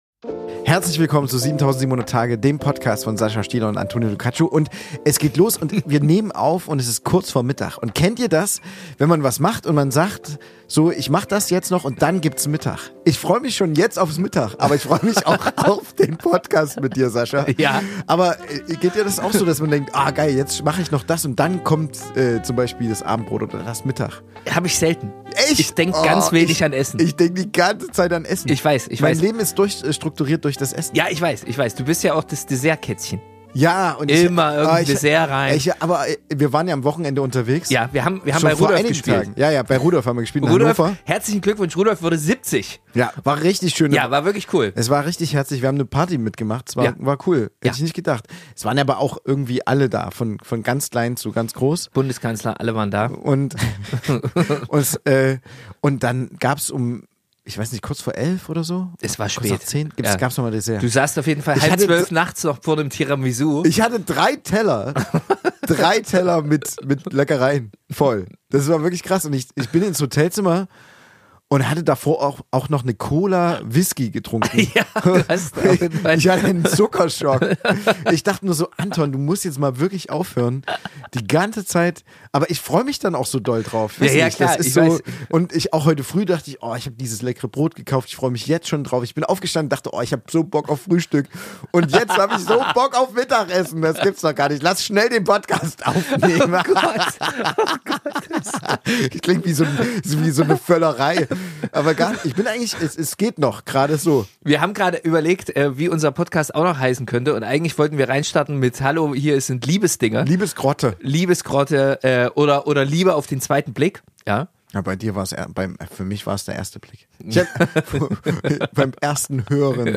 Eine Folge voller Meinungen, Halbwissen und bester Laune.